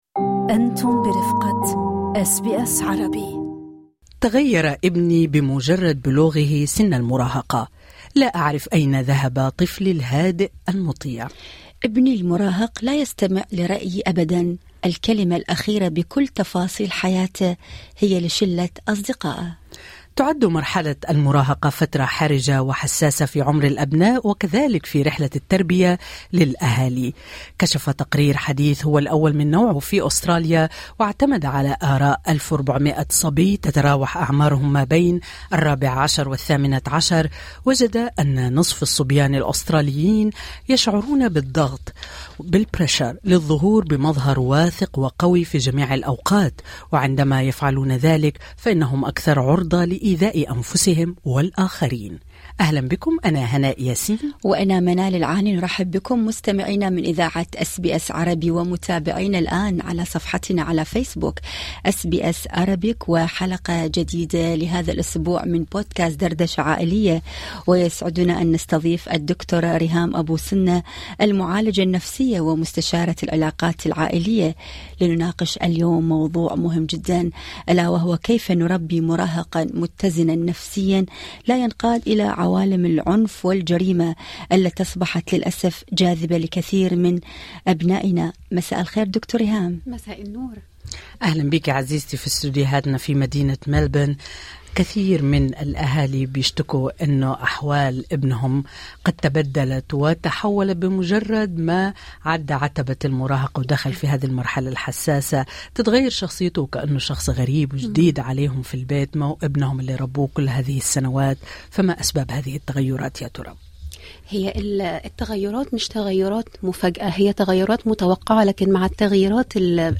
دردشة عائلية: كيف نحمي ابننا المراهق من دخول عالم الجريمة؟ معالجة نفسية تجيب